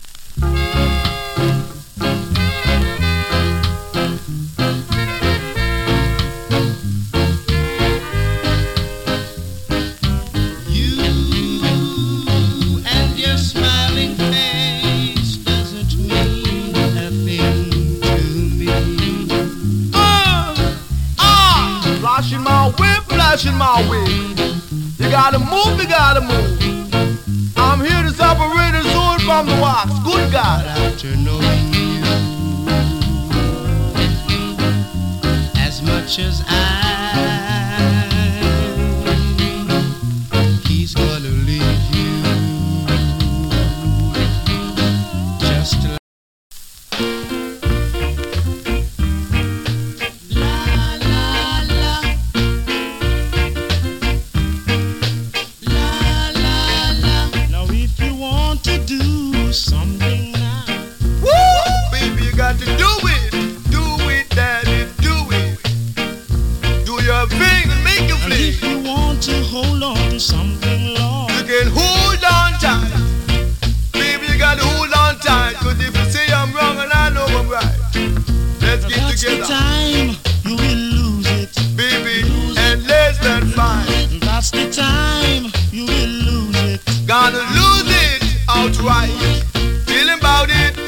SMALL HISS 有り。チリ、パチノイズ少し有り。
DEE-JAY CUT !